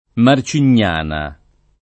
Marcignana [ mar © in’n’ # na ] top. (Tosc.)